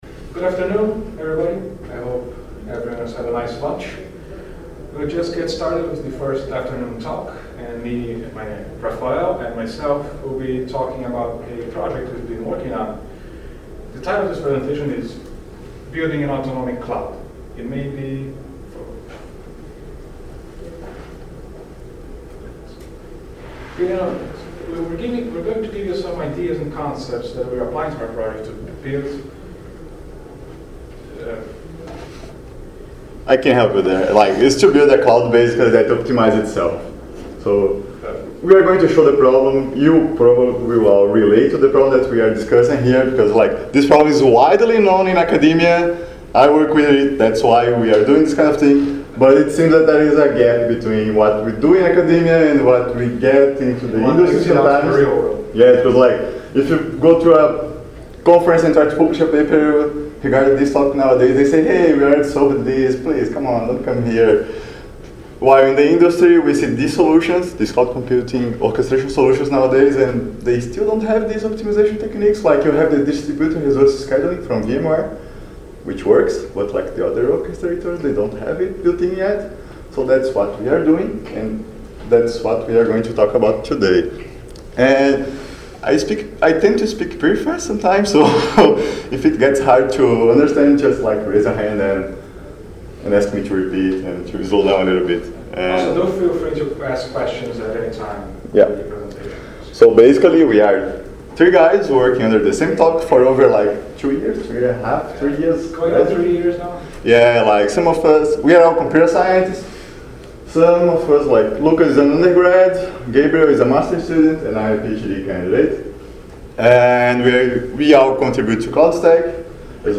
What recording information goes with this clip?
ApacheCon Miami 2017 – Building an Autonomic CloudStack Autonomiccs Cloudstack Collaboration Conference